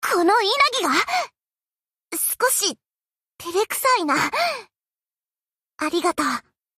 Ship Voice Inagi MVP.mp3
Ship_Voice_Inagi_MVP.mp3.ogg